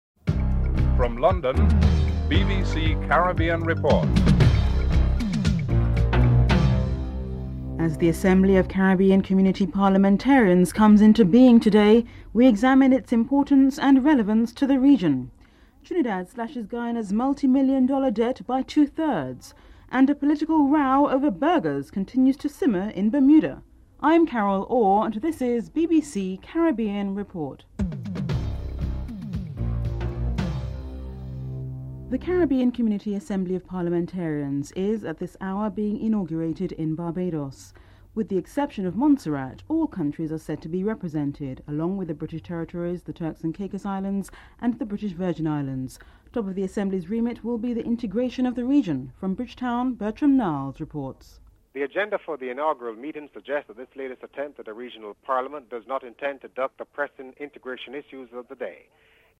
1. Headlines (00:00-00:33)
Sir John Swan is interviewed (11:06-13:00)